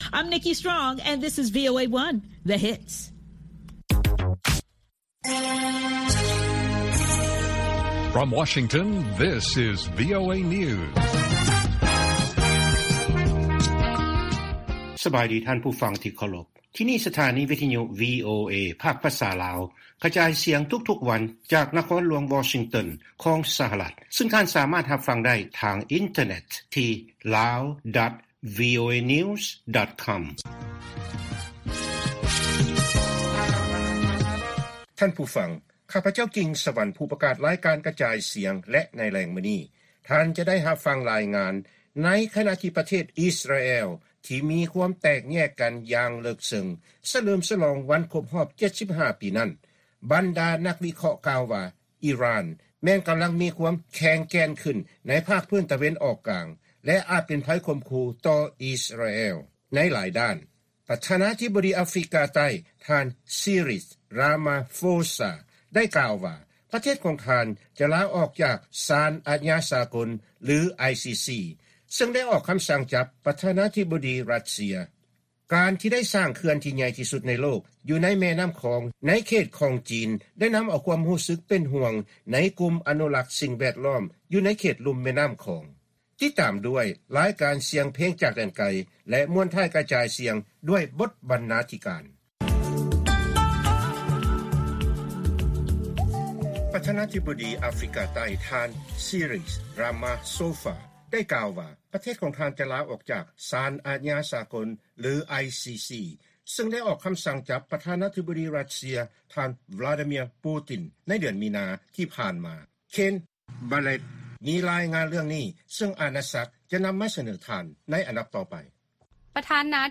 ລາຍການກະຈາຍສຽງຂອງວີໂອເອລາວ: ນັກຊ່ຽວຊານຫວັງວ່າ ຈີນຈະໃຫ້ຄວາມຮ່ວມມືຫຼາຍຂຶ້ນ ກ່ຽວກັບຜົນກະທົບຈາກເຂື່ອນ ຕໍ່ເຂດລຸ່ມແມ່ນ້ຳຂອງ